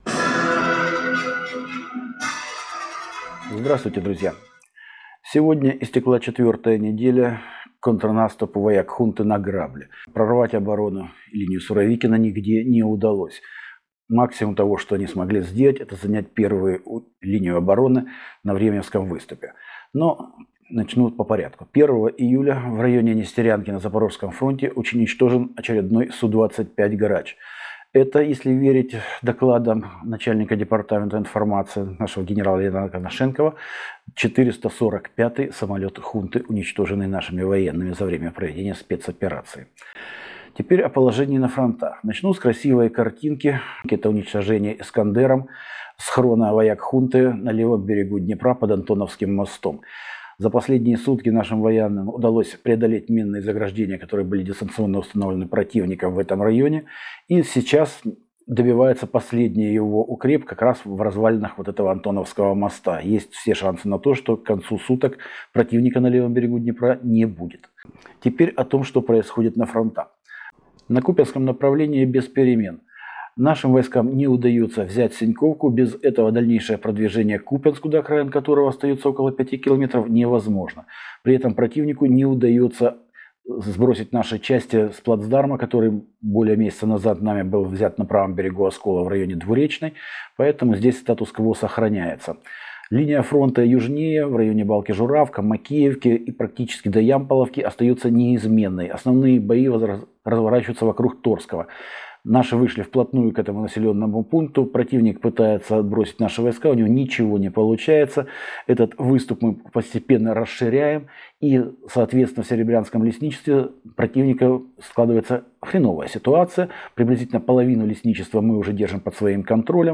Дневная сводка в аудио формате:
svodka-2-iyulya.mp3